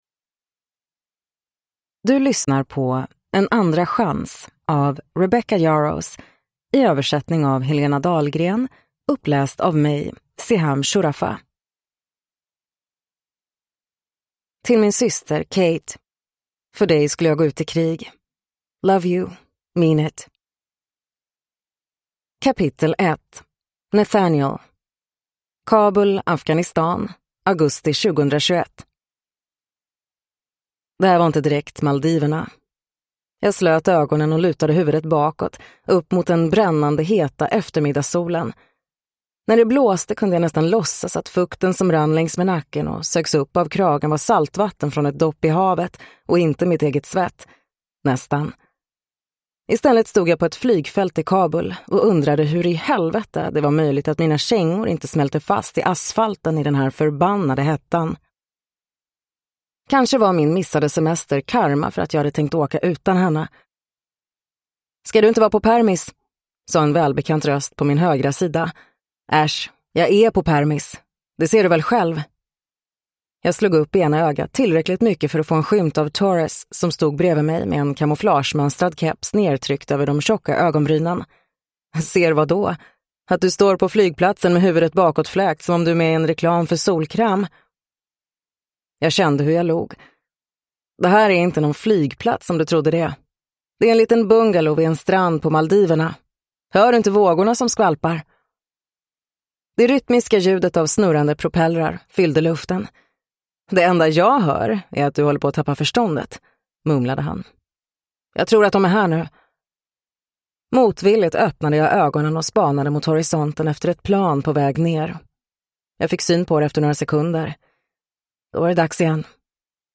En andra chans – Ljudbok